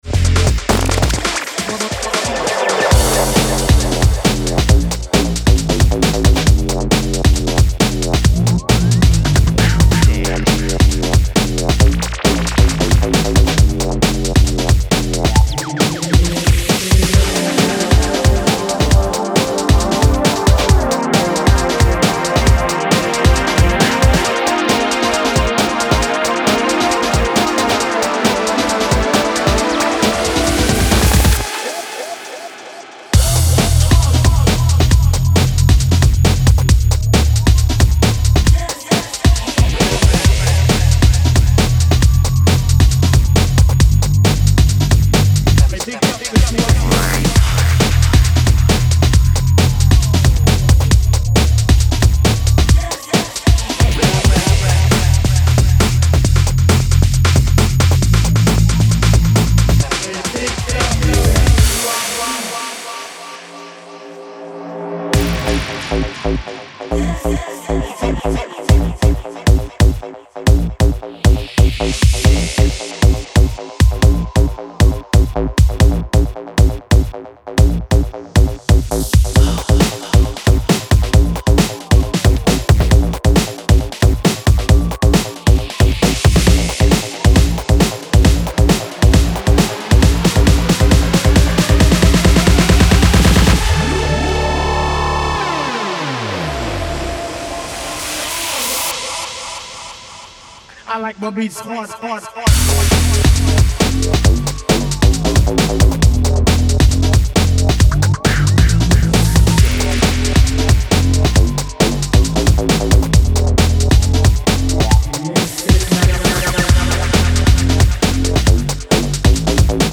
high-octane grooves
fusing speed garage, UKG, and breakbeat heat.